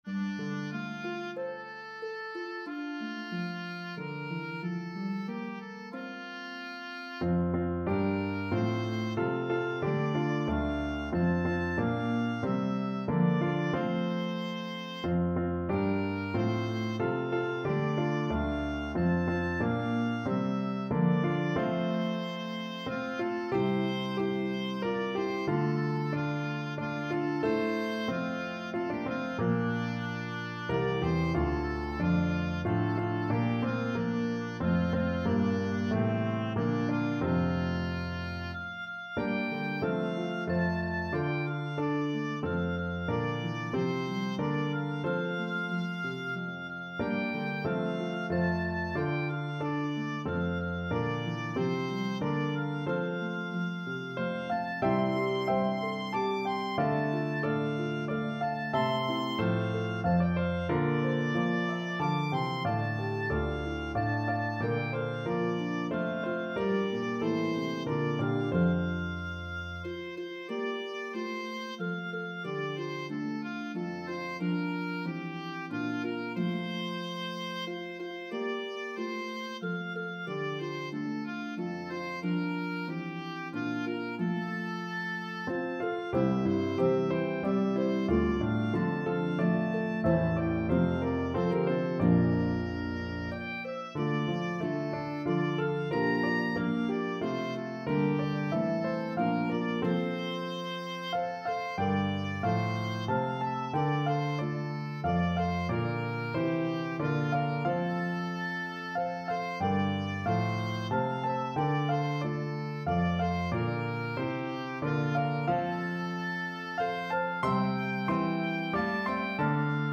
A meditative trio arrangement
The harp part is playable on either Lever or Pedal Harps.
pentatonic hymn tune